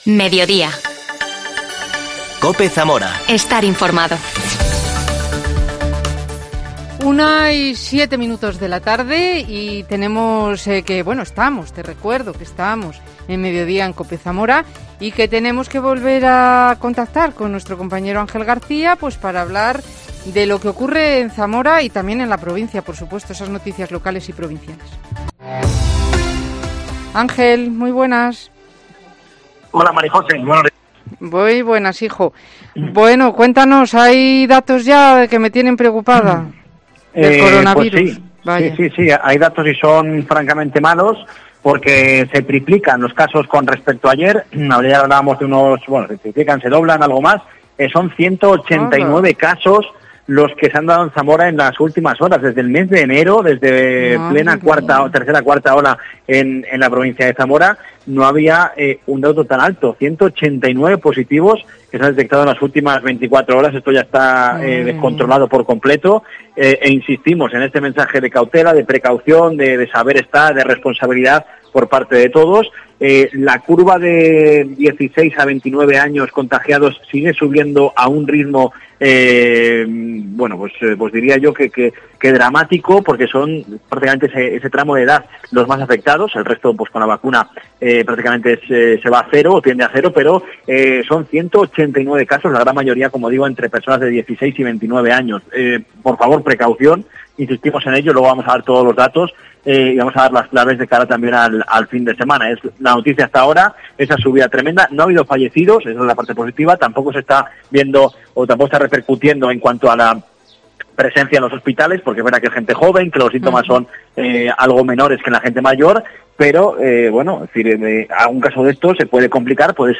AUDIO: Charla con el grupo de música de Zamora, Titis Twister